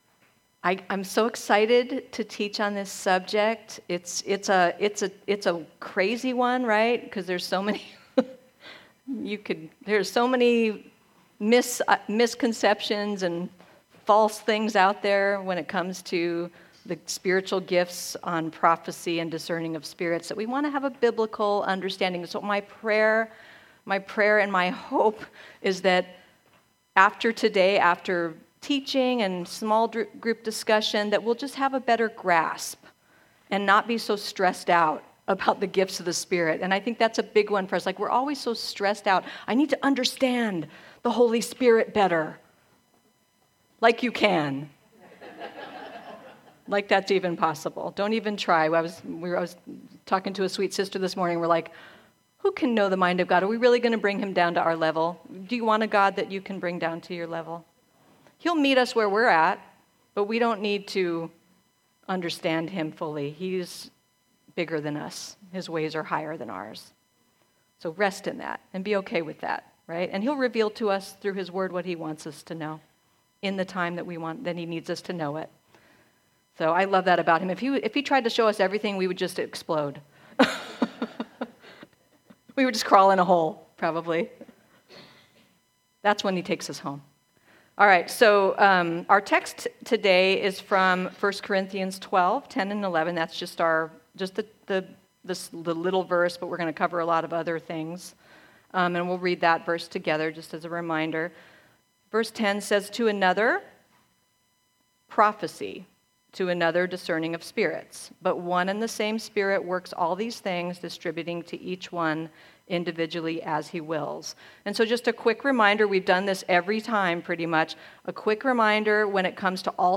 A message from the series "Women of the Word."